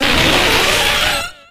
Audio / SE / Cries / PARAS.ogg
PARAS.ogg